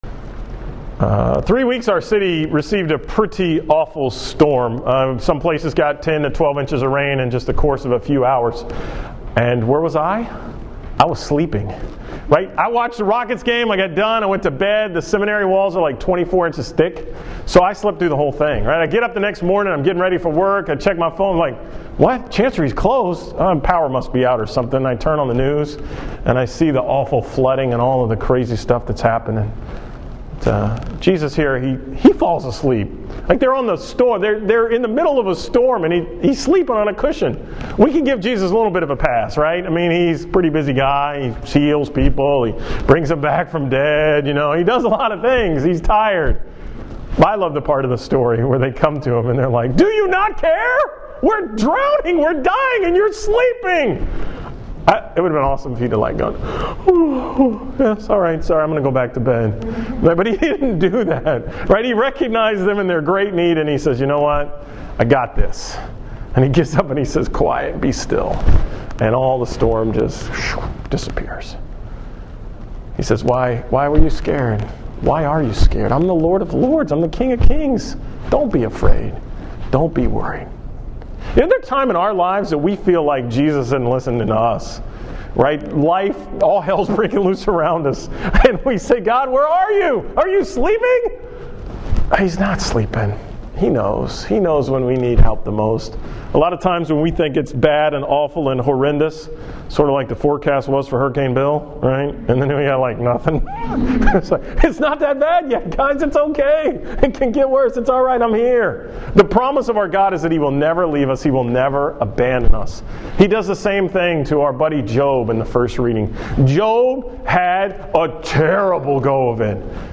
From the June Engaged Encounter Retreat at Cameron retreat Center on June 21, 2015.